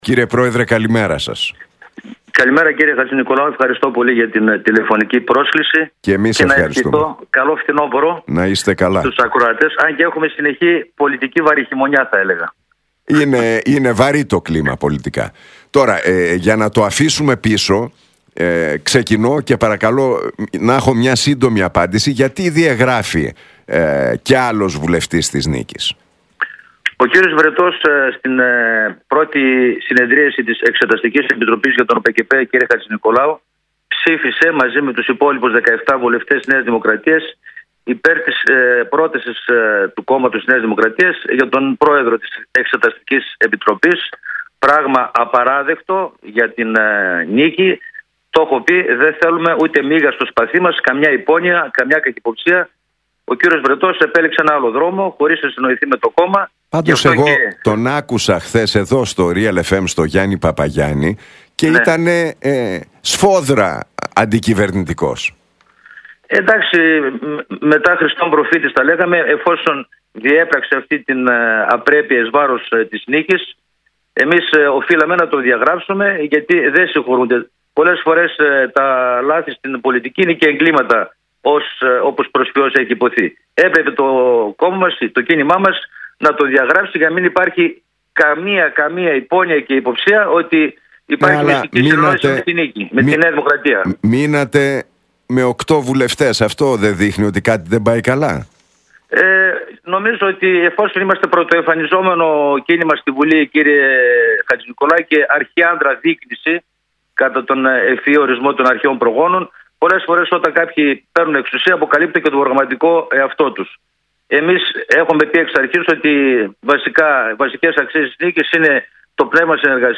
Στην εκπομπή του Νίκου Χατζηνικολάου μίλησε σήμερα ο πρόεδρος της «Νίκης», Δημήτρης Νατσιός.